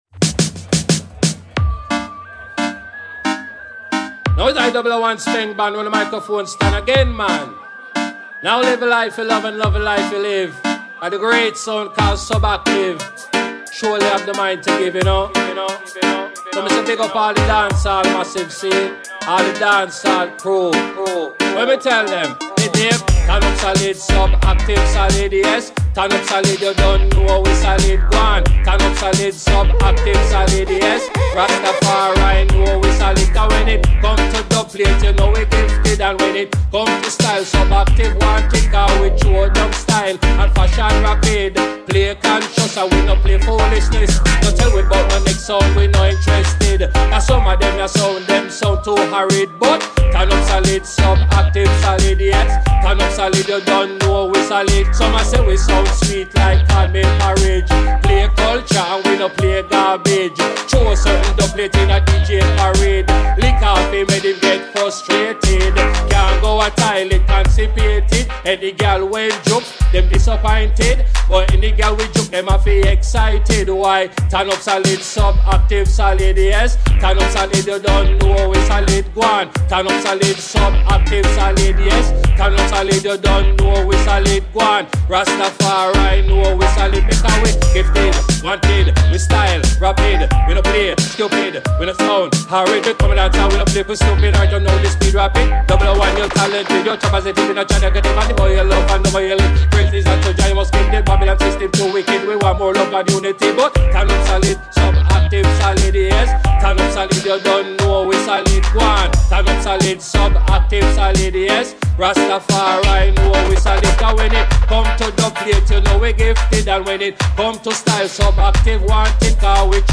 Live recording
in session